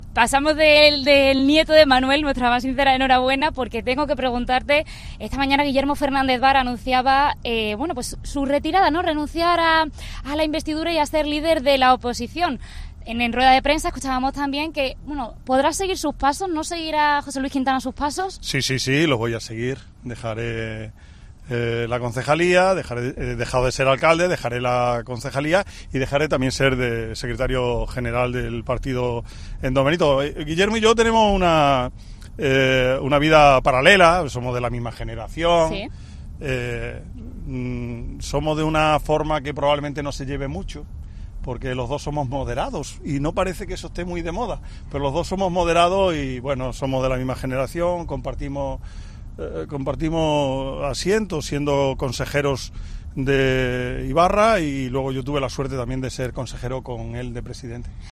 Quintana ha confirmado su decisión en la cadena COPE, durante la sección 'en Ruta con Maven e Hijos' que realizamos cada martes y en la que entrevistamos a un personaje del lugar por donde discurre la ruta, en este caso Don Benito.